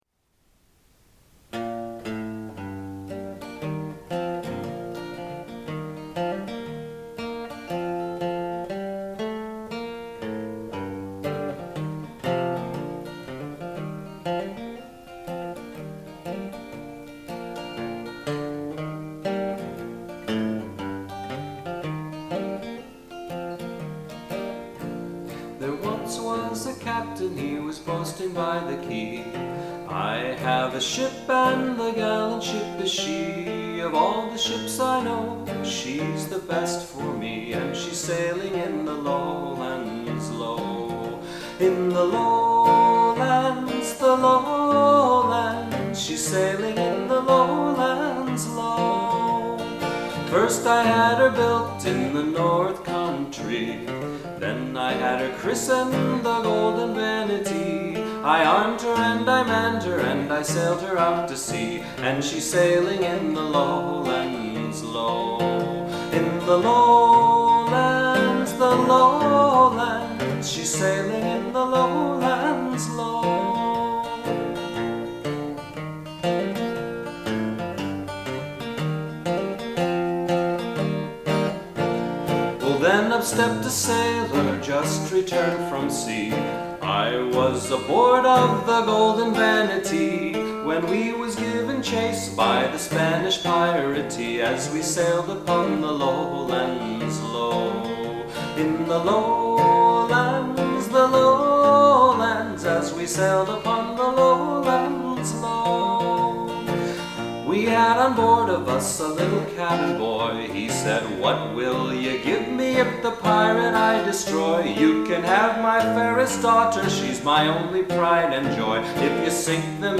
One of the most popular ballads I taught to many students over the years is The Golden Vanity, an English ballad that exists in many versions; the oldest known surviving version The Sweet Trinity dates from the early 17th century, and Aaron Copland included a version The Golden Willow Tree as part of his Old American Songs (Set 2).
voice & guitar ~ November 25